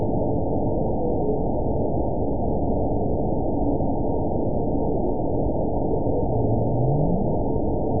event 919795 date 01/24/24 time 03:18:25 GMT (1 year, 3 months ago) score 9.60 location TSS-AB01 detected by nrw target species NRW annotations +NRW Spectrogram: Frequency (kHz) vs. Time (s) audio not available .wav